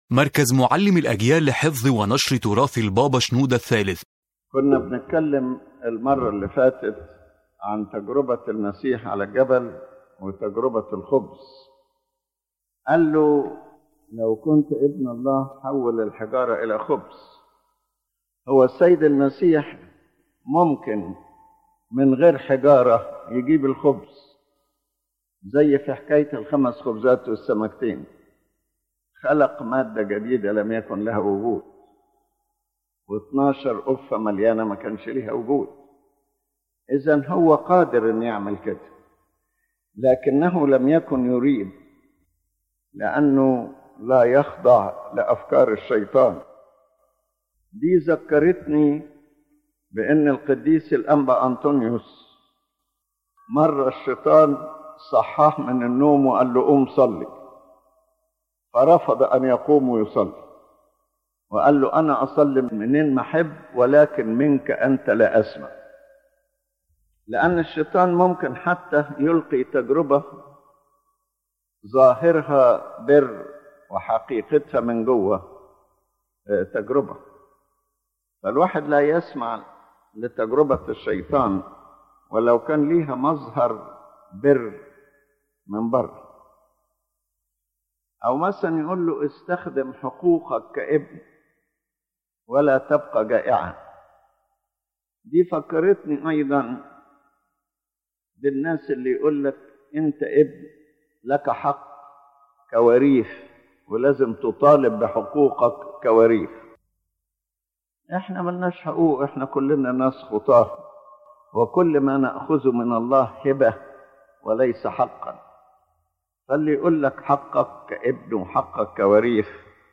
The lecture revolves around how the Lord Christ faced the temptations presented to Him by Satan, especially the temptation of turning stones into bread and the temptation of throwing Himself from the pinnacle of the temple. His Holiness Pope Shenouda III reveals that Christ did not only refuse the temptation, but also established firm spiritual principles from which we learn the way of victory over the devil.